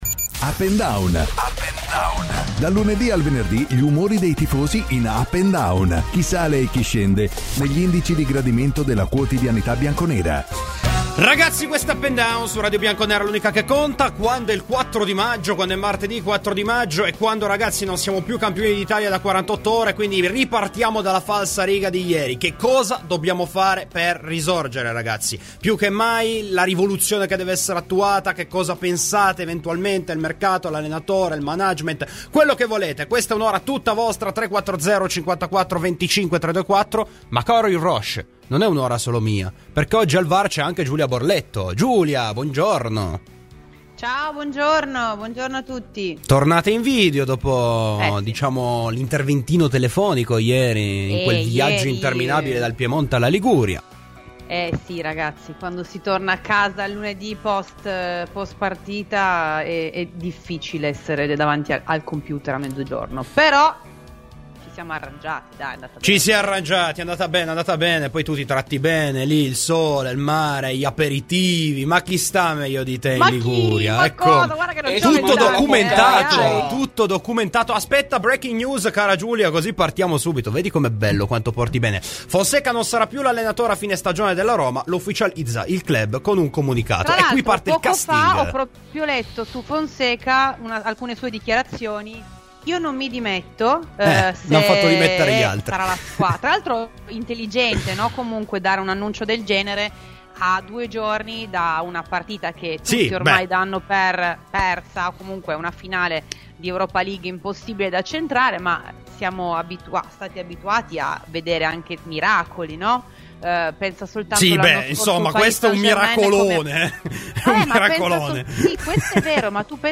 Ronaldo al momento è un problema per la Juventus, i bianconeri si ritrovano un contratto assolutamente anti-storico. Se qualcuno venisse a prendersi il portoghese alla Juventus brinderebbero Clicca sul podcast in calce per ascoltare la trasmissione integrale.